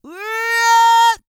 Index of /90_sSampleCDs/ILIO - Vocal Planet VOL-5 - World Voices/Partition B/5 CARIBBEAN